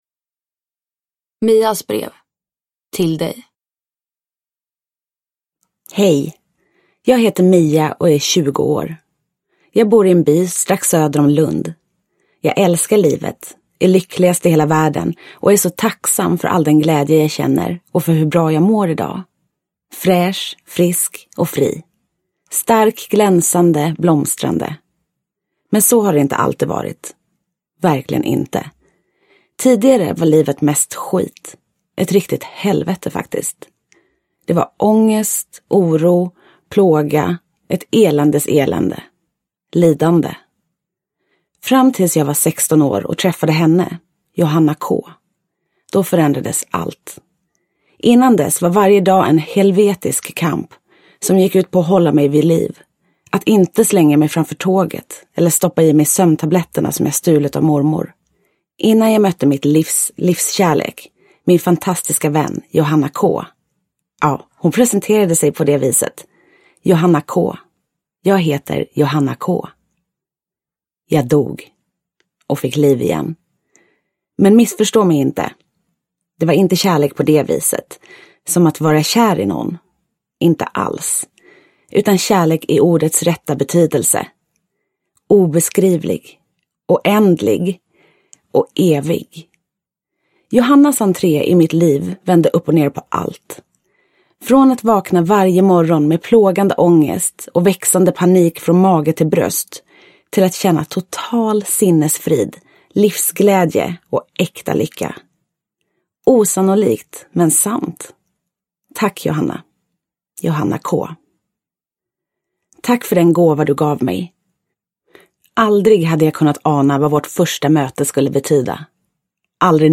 Jag dog och fick liv igen (ljudbok) av Dennis Westerberg